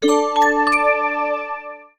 silkyalert.wav